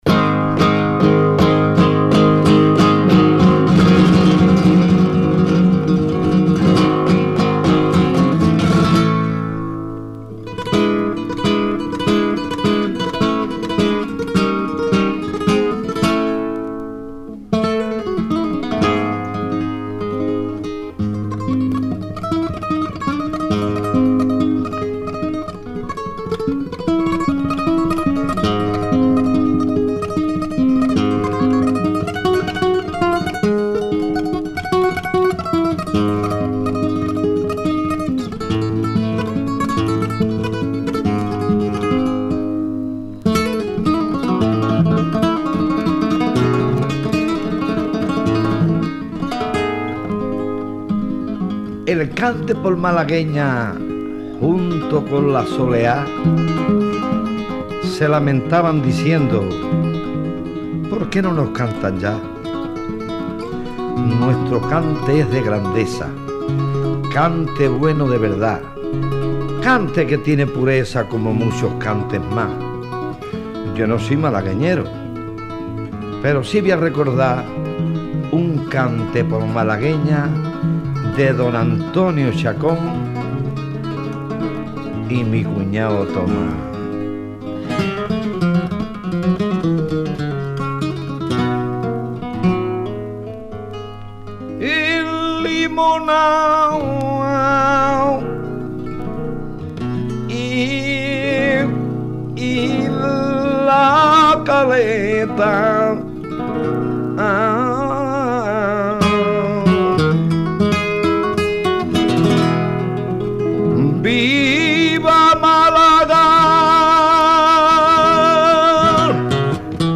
Malagueña n°1